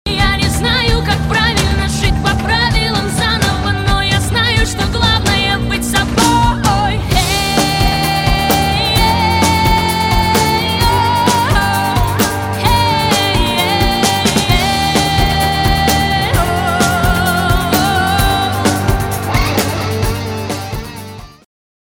• Качество: 128, Stereo
красивый женский вокал
alternative
indie rock